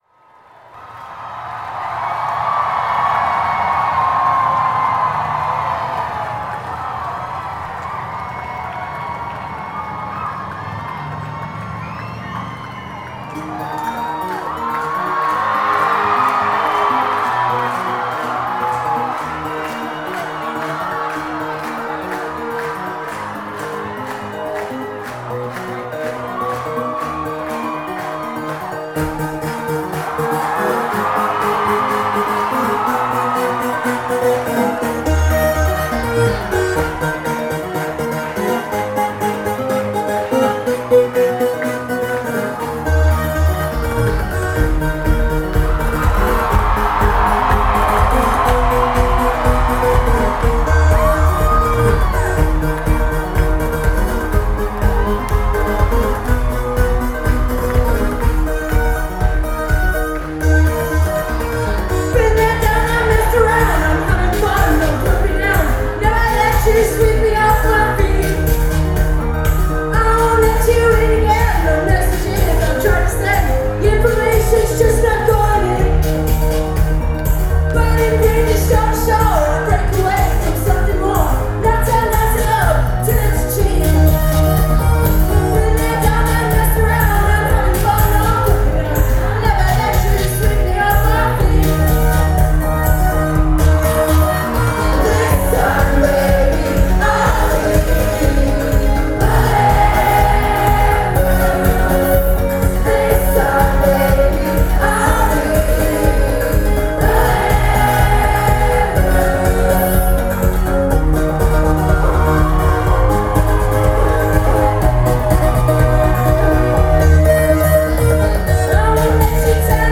straight-to-the-point pop songs in the vein of 80s groups